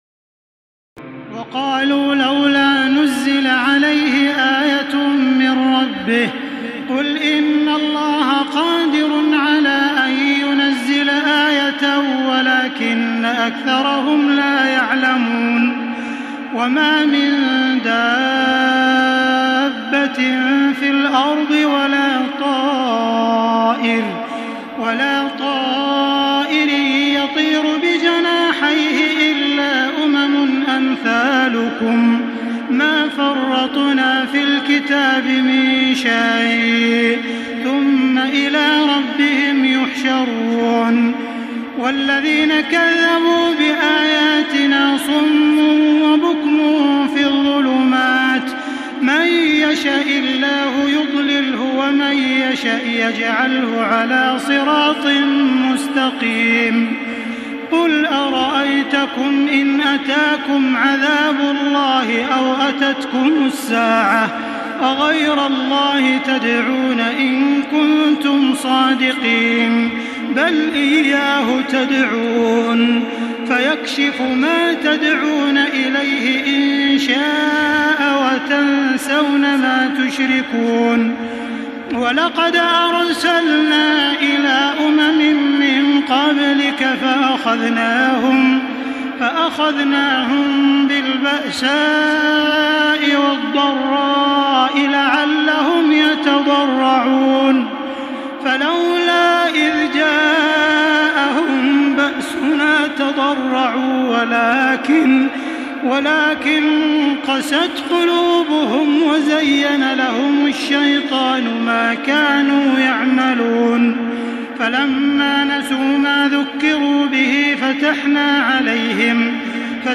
تراويح الليلة السابعة رمضان 1435هـ من سورة الأنعام (37-111) Taraweeh 7 st night Ramadan 1435H from Surah Al-An’aam > تراويح الحرم المكي عام 1435 🕋 > التراويح - تلاوات الحرمين